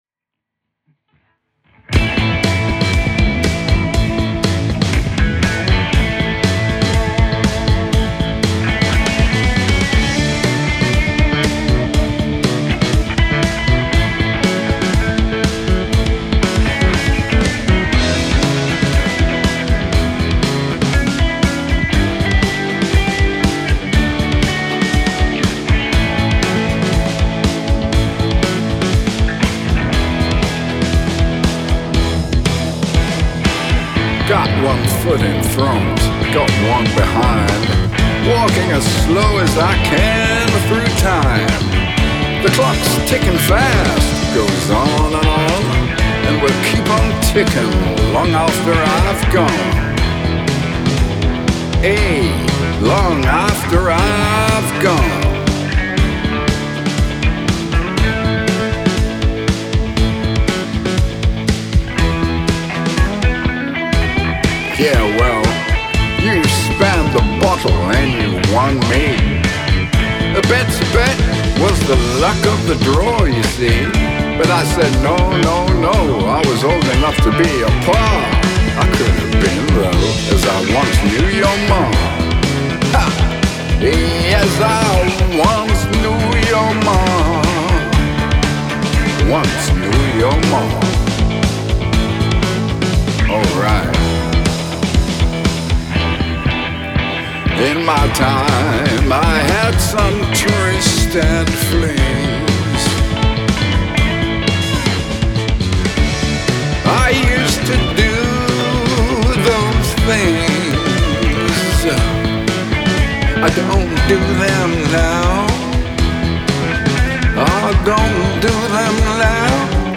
a poignant, reflective blues narrative